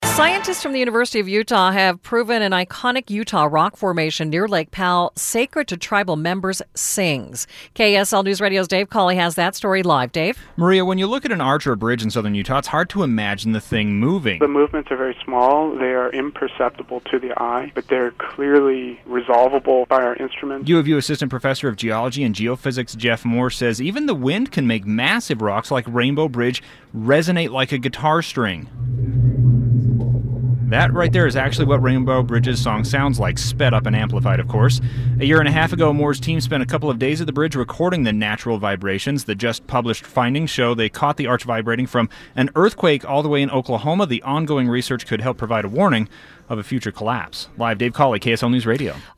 Scientists studying the structural resonances of Utah's arches and natural bridges caught the sound of Rainbow Bridge. They say the rocks are not solid and still as they appear, but instead vibrate constantly.